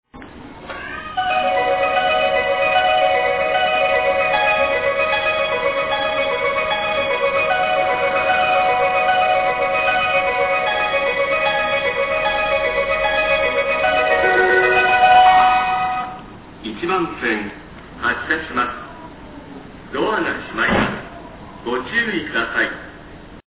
「久里浜１番線発車メロディ」
Kurihama1hassya.mp3